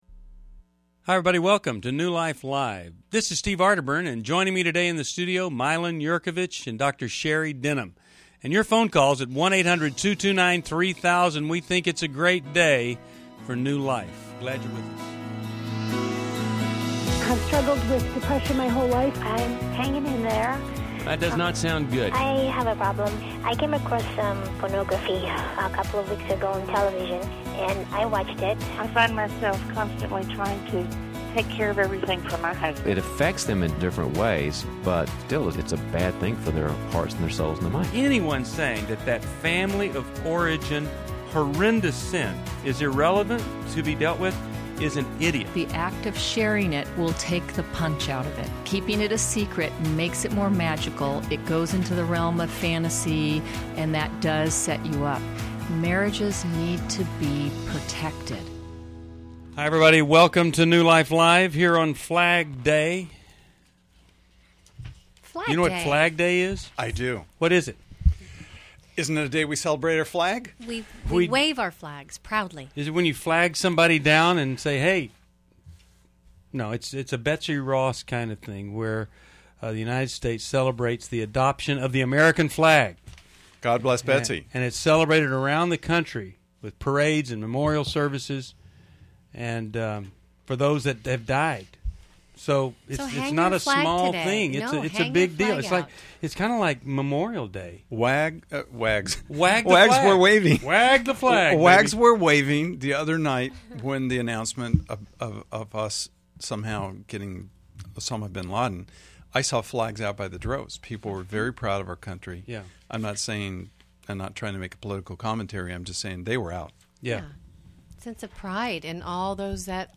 Caller Questions: 1. How do I reconnect with my wife after 3yrs of separation? 2.